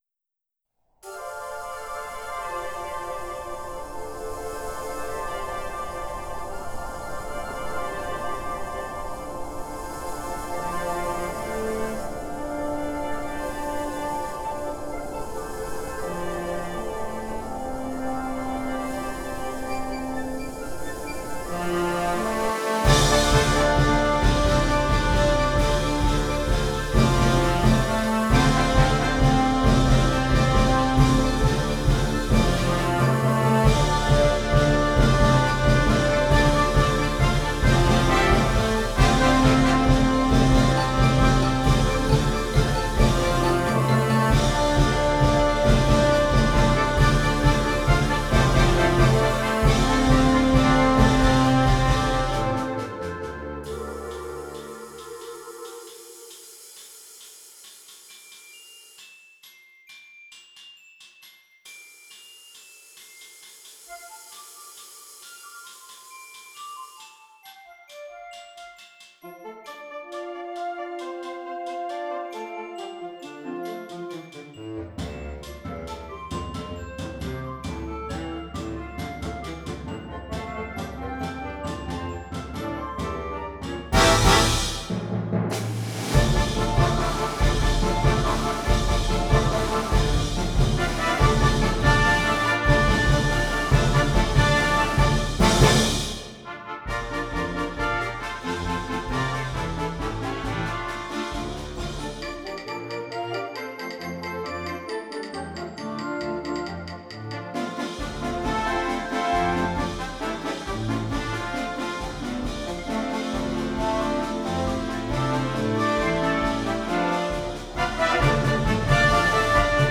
• Piccolo
• Flauta 1
• Oboe
• Fagot 1
• Clarinete en Bb 1
• Saxofón Alto 1
• Trompeta en Bb 1
• Corno en F 1
• Trombón 1
• Tuba
• Timbal
· Bombo andino o